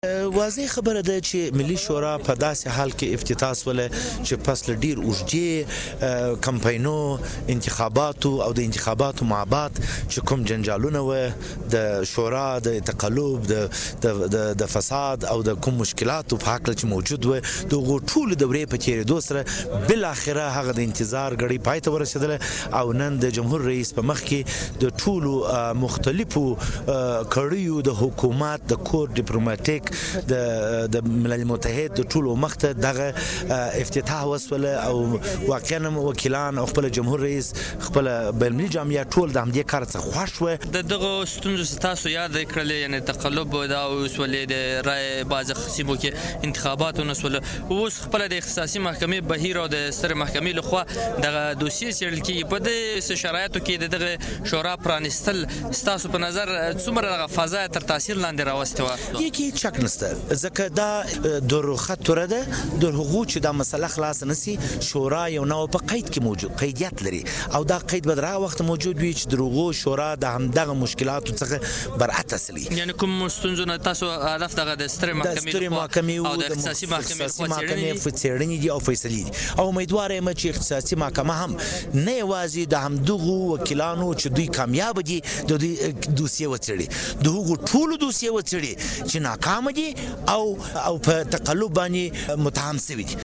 خالد پښتون سره مرکه